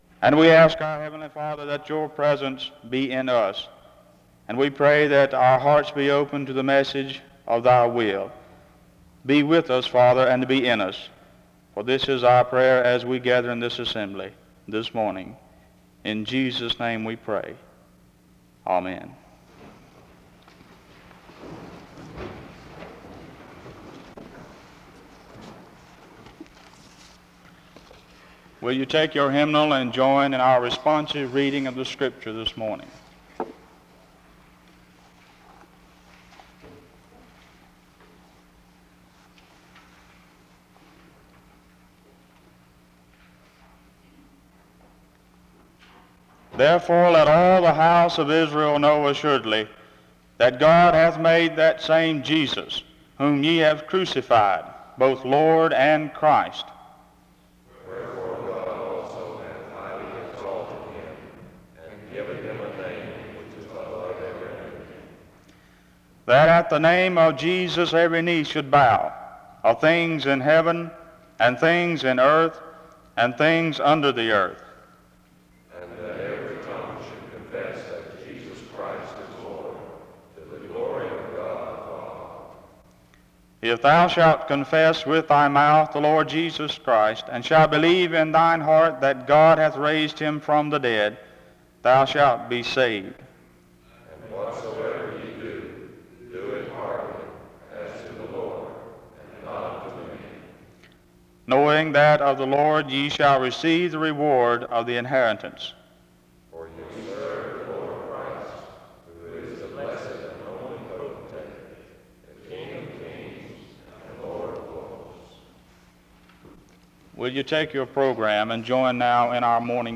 Download .mp3 Description This service was organized by the Student Coordinating Council. The service begins with a prayer and a responsive reading (0:00-3:44). The first speaker explains how Jesus loved through his sacrifice on the cross (3:45-9:41). The second speaker explains how believers must speak the truth of the Gospel in love (9:42-19:30). He concludes by challenging his audience to consider how they are living (19:31-21:16) The service closes in prayer (21:17-22:06).